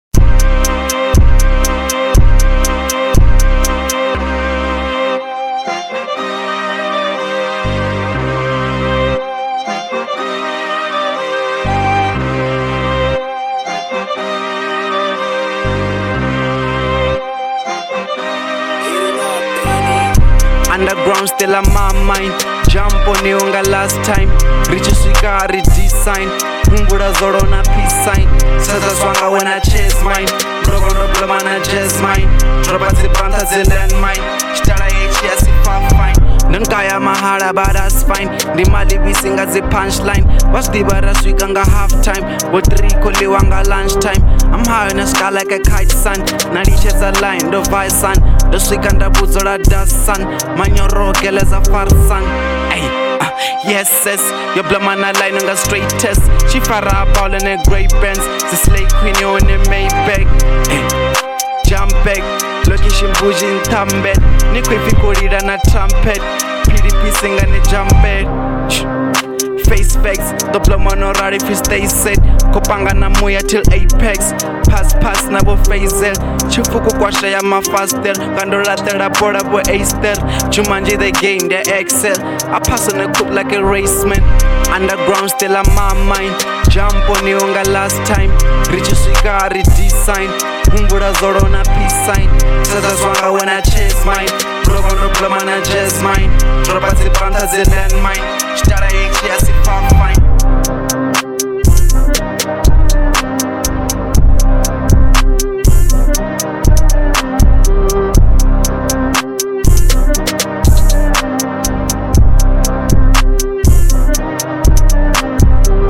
01:55 Genre : Venrap Size